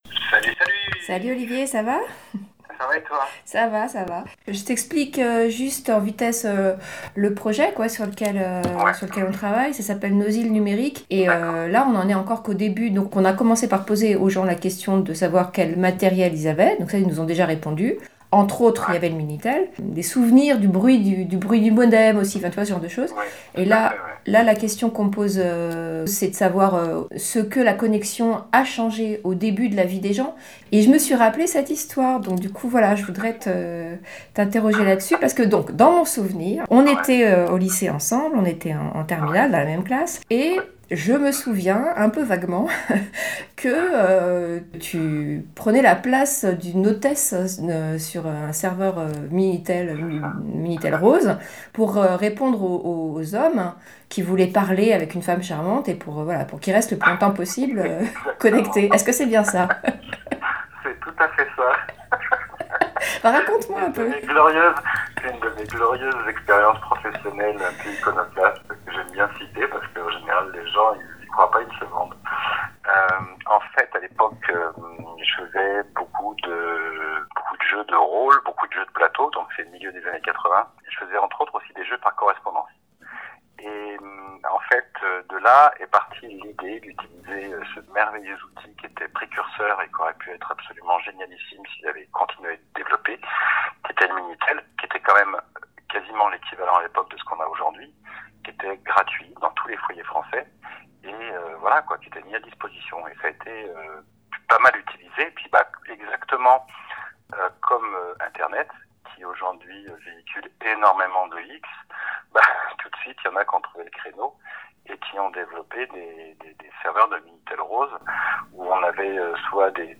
J’ai voulu savoir ce qu’il pensait de ses premières années de connexion et, après un contact via la messagerie d’un réseau social, je l’ai appelé, tout simplement ! Résultat : un entretien en deux épisodes, dont voici le premier.